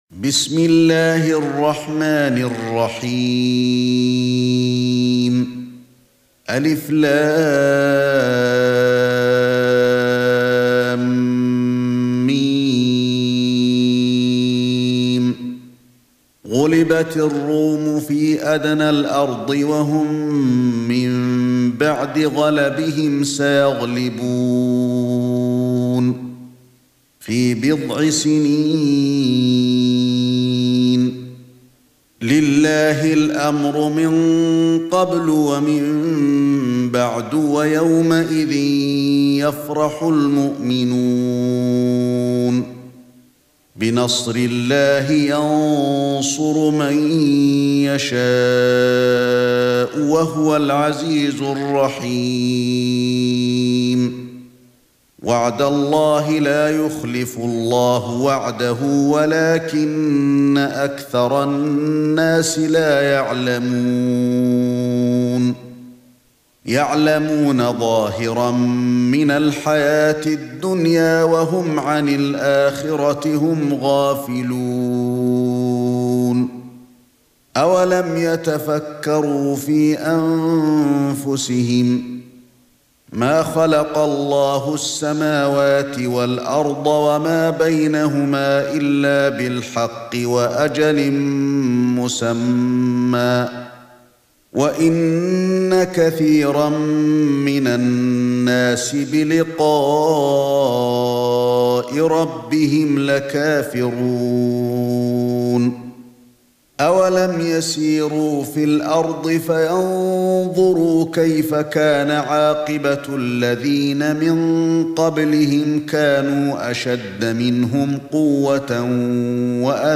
سورة الروم ( برواية قالون ) > مصحف الشيخ علي الحذيفي ( رواية قالون ) > المصحف - تلاوات الحرمين